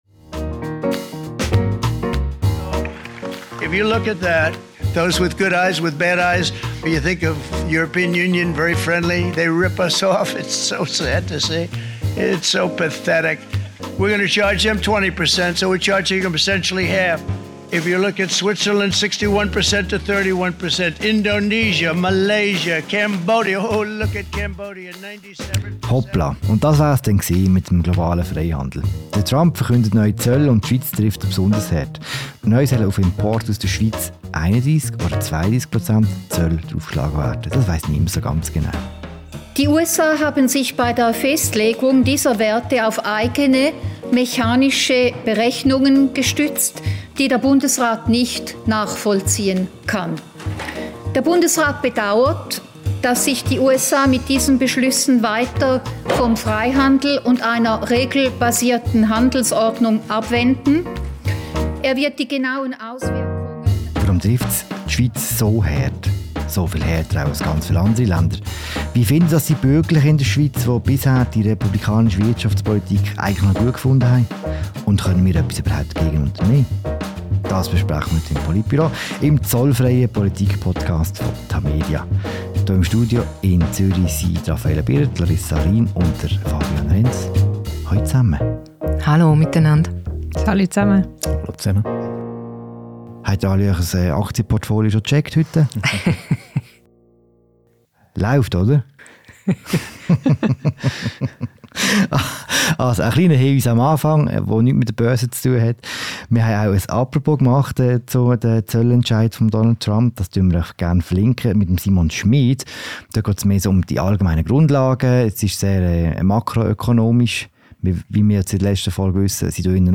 Die Zölle der US-Regierung treffen die Schweiz besonders hart. Im Inland-Podcast «Politbüro» ordnet die Redaktion die politischen Reaktionen ein.